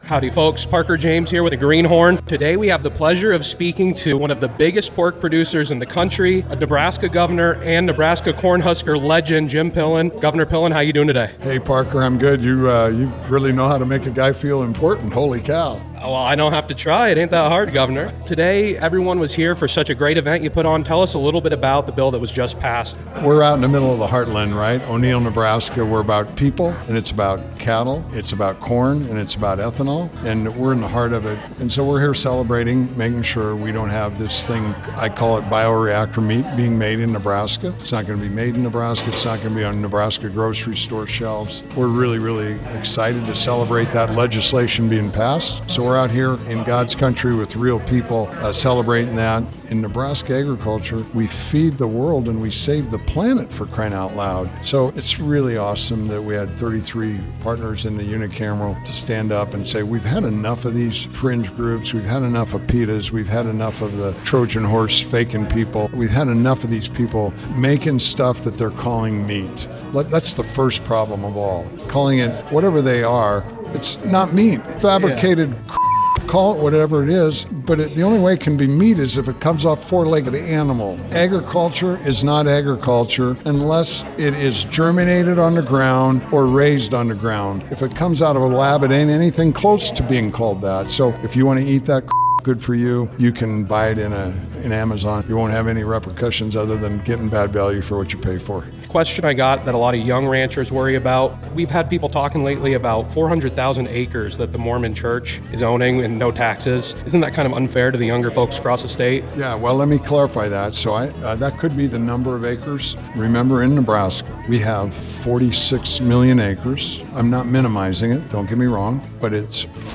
Greenhorn #49: Special Interview with Nebraska Governor Jim Pillen!